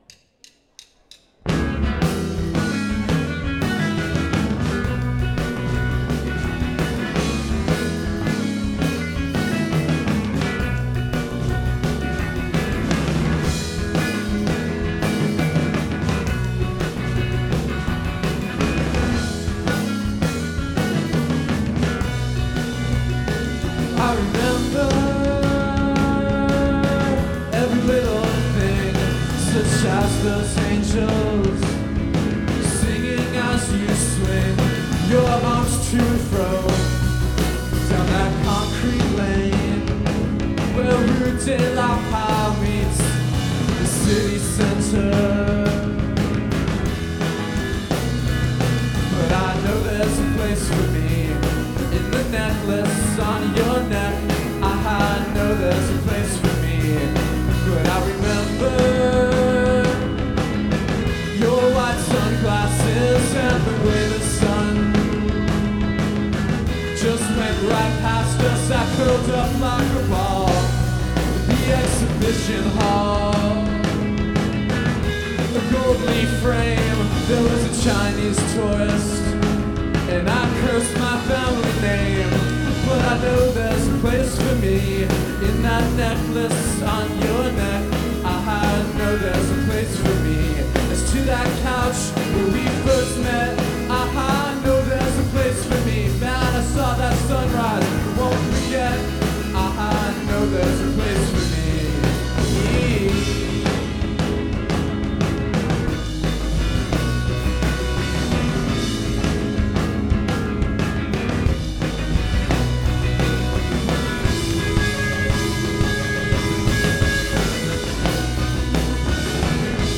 pop tra Smiths e Go-Betweens
live at Bell House, NY